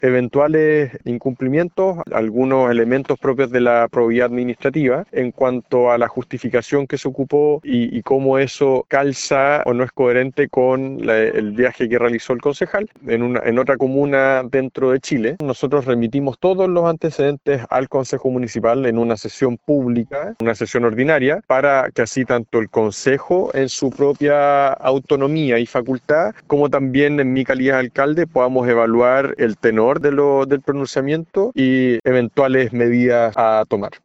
Al respecto, el alcalde de Puerto Varas, Tomás Gárate, señaló que tras el informe que entregaron, desde Contraloría constataron eventuales incumplimientos a la probidad administrativa.